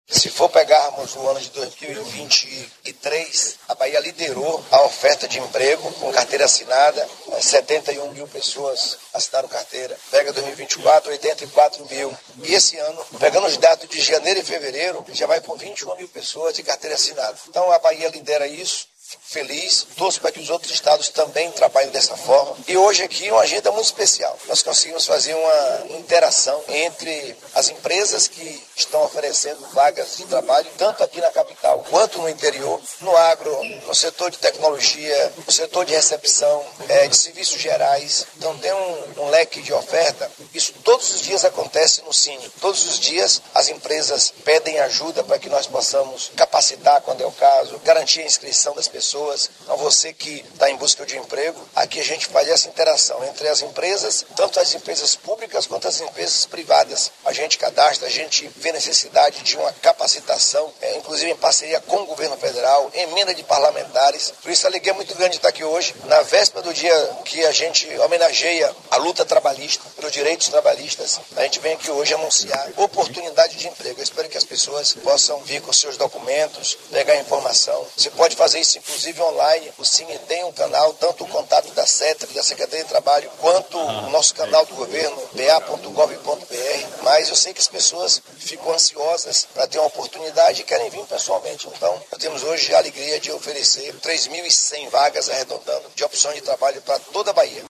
Confira a fala do governador: